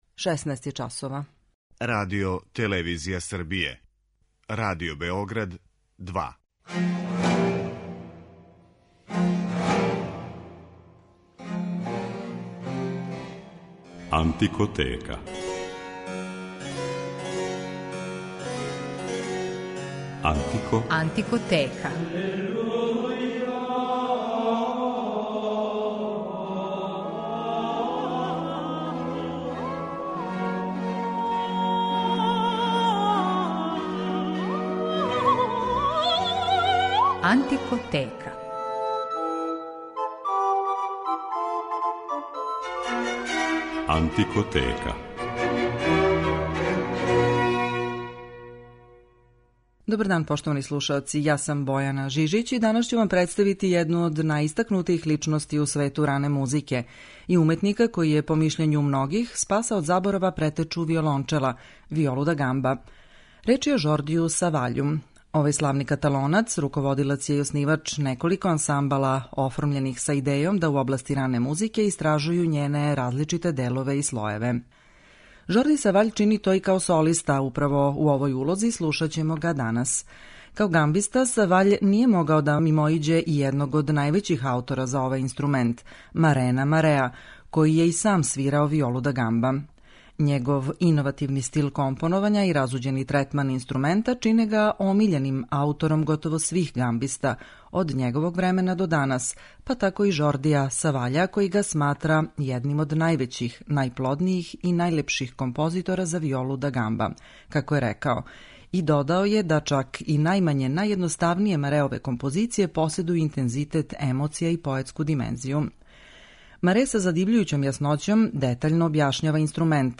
Овог славног Каталонца ћемо слушати овога пута као солисту у извођењу дела Марена Мареа, Дијега Ортиза, Тобајаса Хјума, као и композиција из збрике 17. века "Манчестерска" књига за виолу.